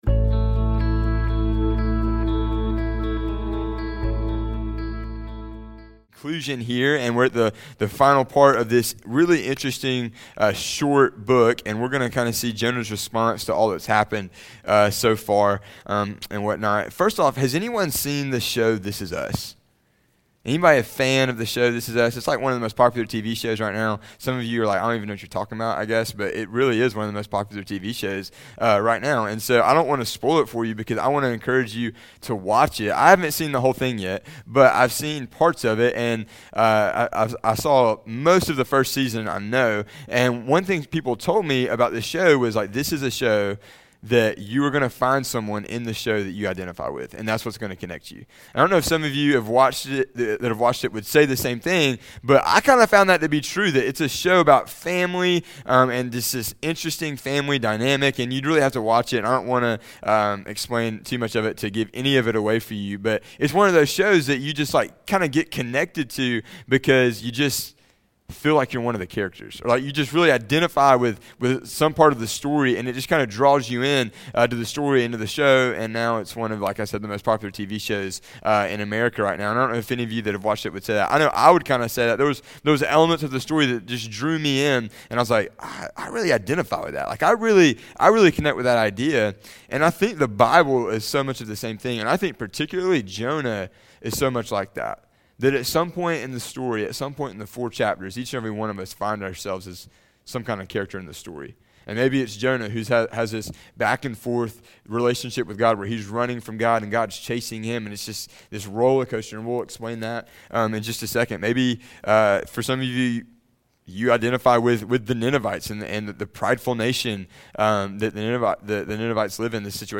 In our final sermon of the Jonah Series